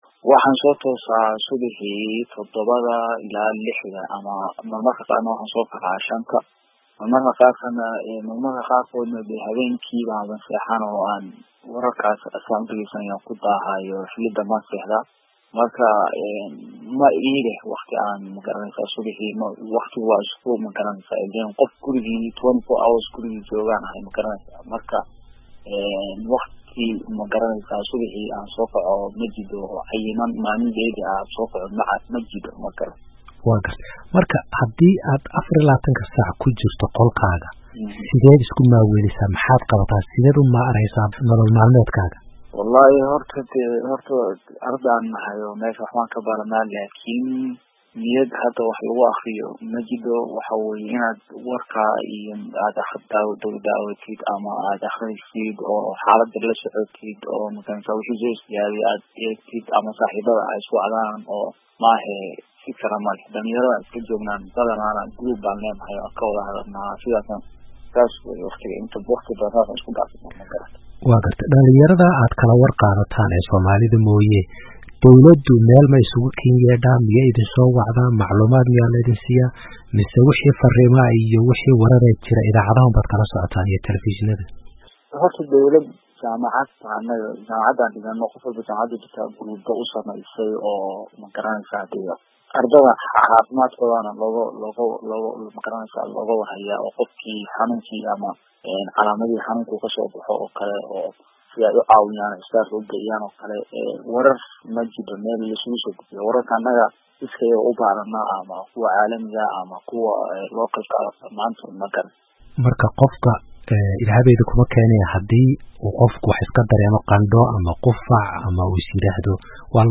Waraysi arday ku sugan Wuhan, Shiinaha